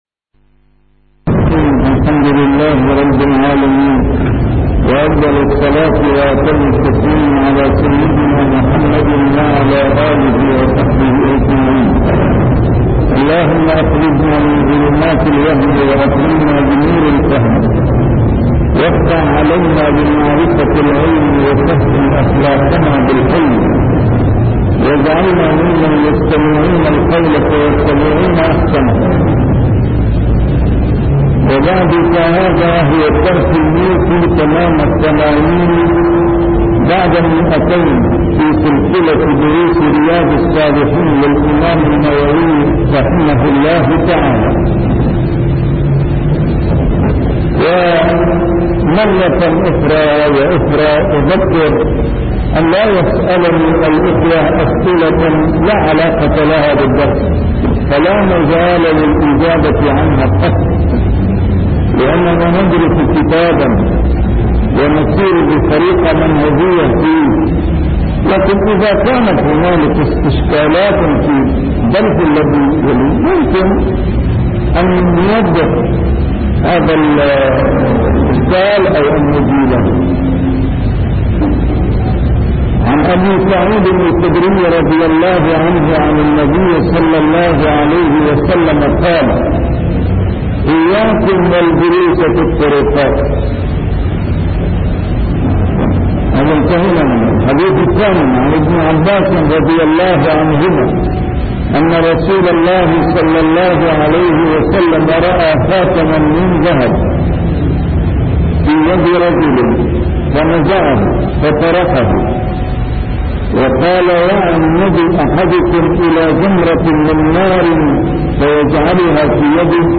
A MARTYR SCHOLAR: IMAM MUHAMMAD SAEED RAMADAN AL-BOUTI - الدروس العلمية - شرح كتاب رياض الصالحين - 280- شرح رياض الصالحين: الأمر بالمعروف